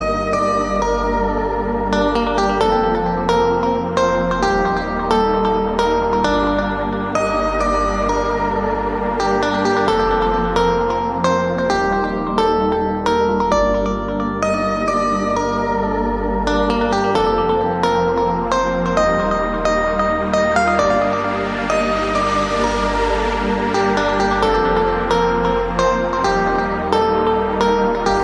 Klingelton Trance Music